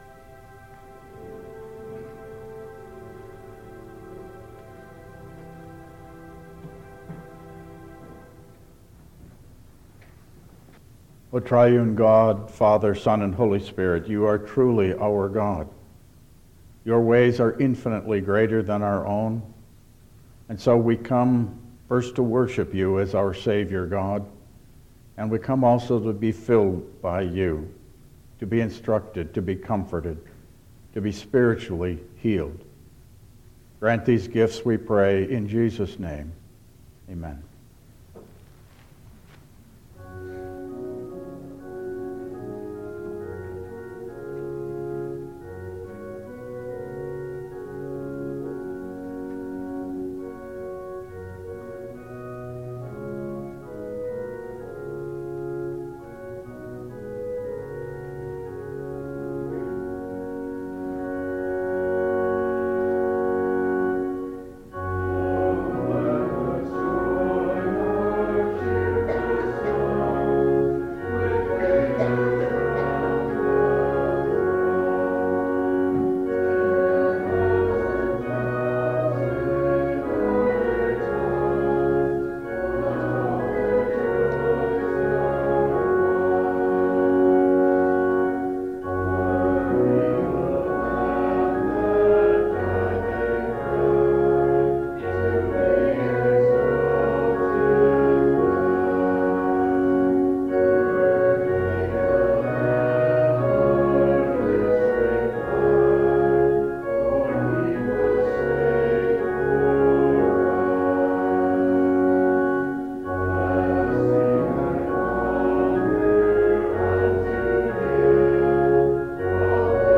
Download Files Printed Sermon and Bulletin
Passage: Deuteronomy 30:15-20 Service Type: Regular Service